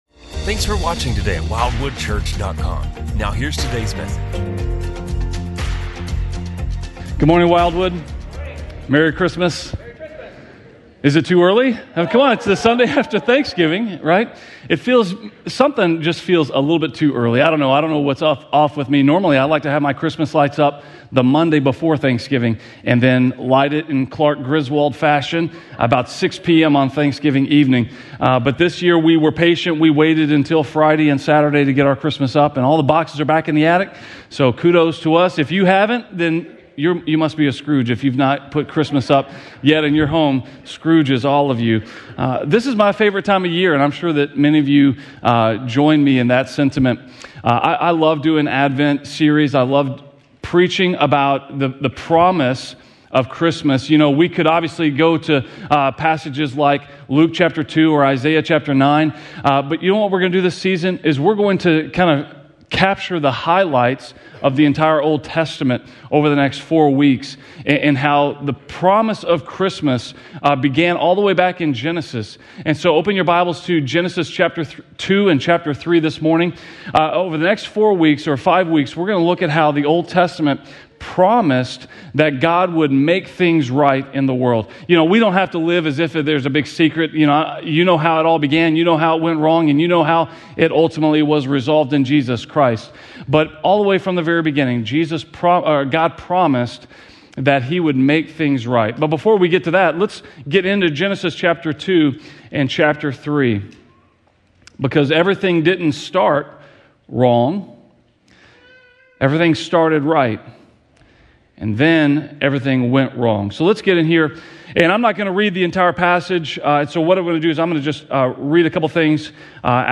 A message from the series "Promise of Christmas."